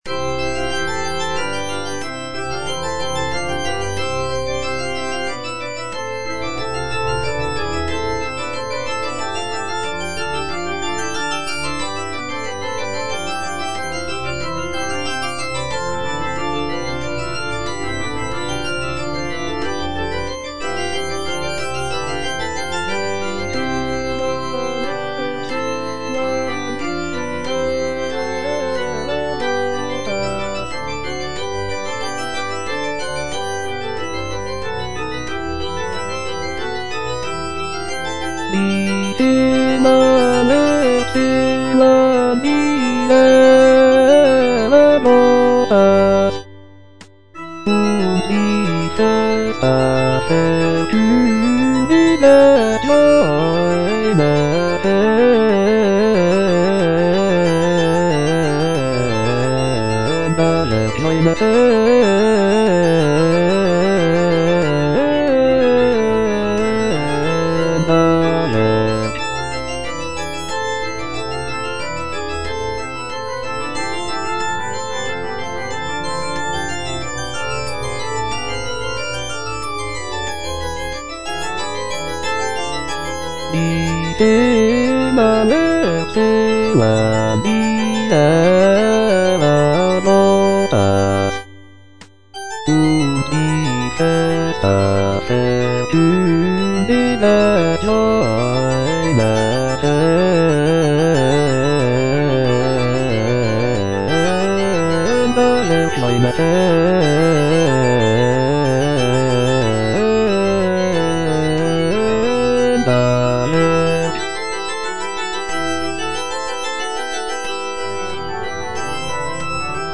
Choralplayer playing Cantata